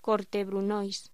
Locución: Corte brunoise
voz